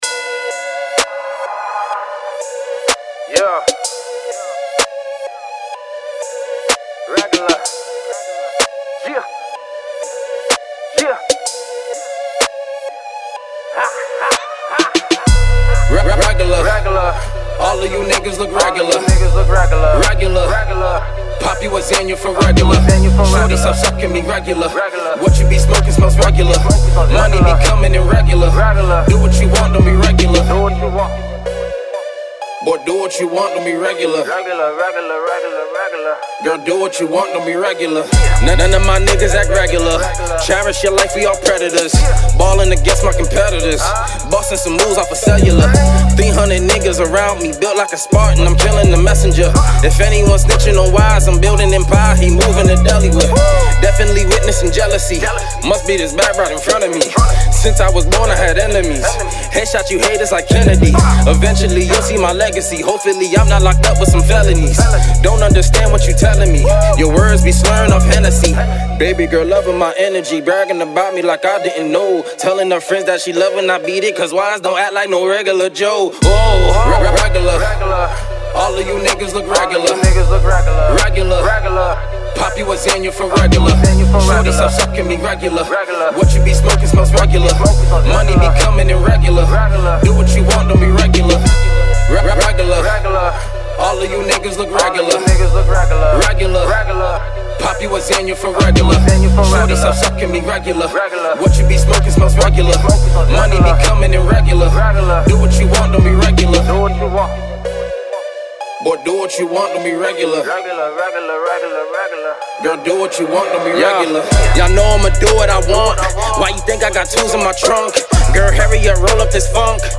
Hiphop
Club Banger